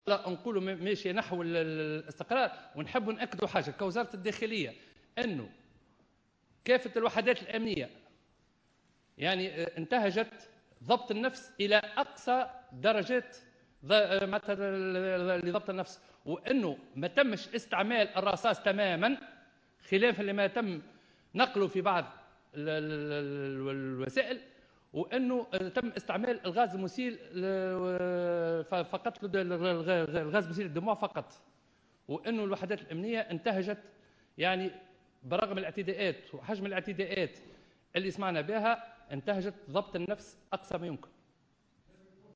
lors d'une conférence de presse